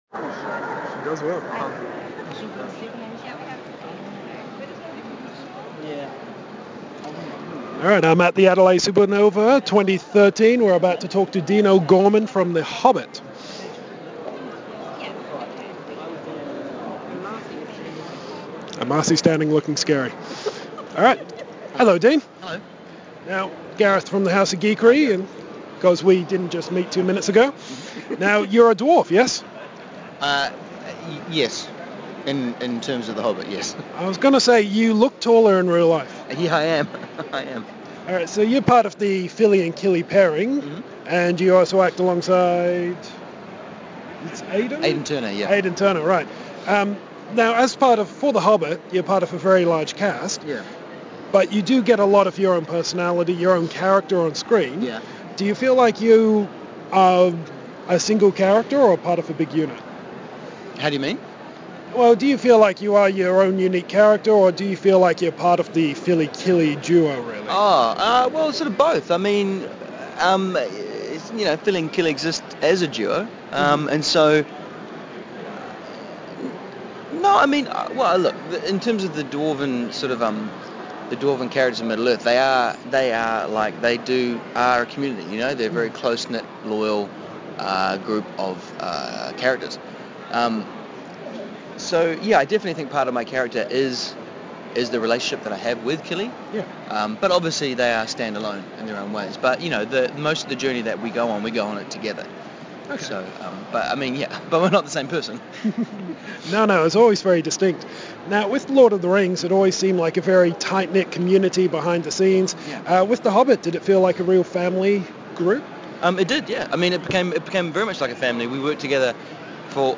As the final part of our Adelaide Supanova coverage we have an interview with Dean ‘Fili’ O’Gorman! We talk about the Middle-Earth experience and what it’s like being part of a horder of dwarves.